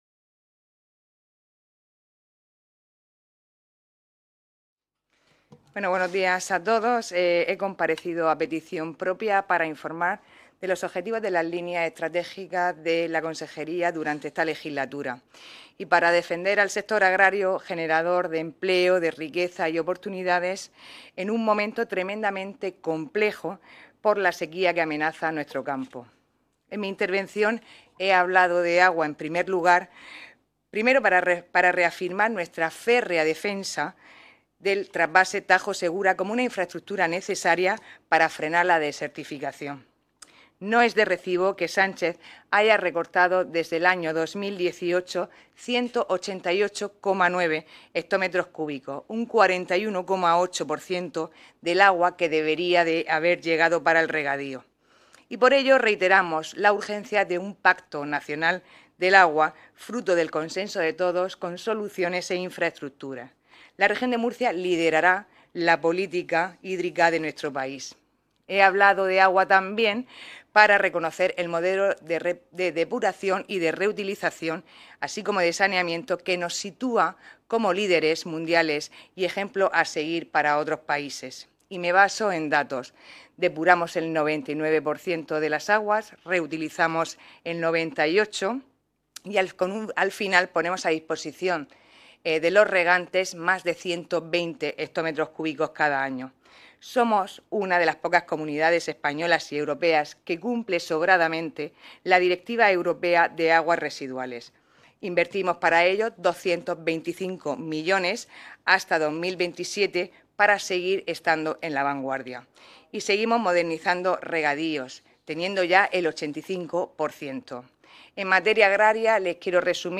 Ruedas de prensa tras la Sesión Plenaria de control al Consejo de Gobierno
• Sara Rubira Martínez, consejera de Agua, Agricultura, Ganadería y Pesca